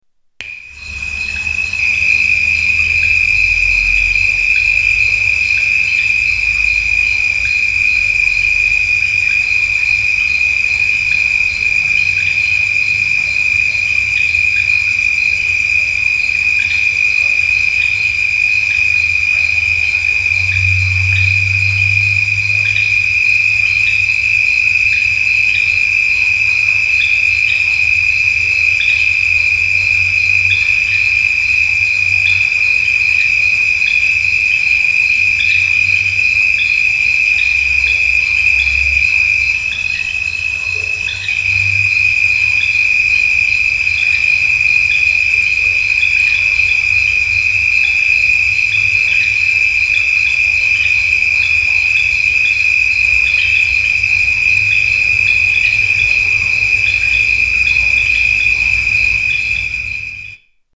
Frogs by the bridge at Bel 2